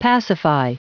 Prononciation du mot pacify en anglais (fichier audio)